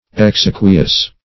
exequious - definition of exequious - synonyms, pronunciation, spelling from Free Dictionary Search Result for " exequious" : The Collaborative International Dictionary of English v.0.48: Exequious \Ex*e"qui*ous\, a. Funereal.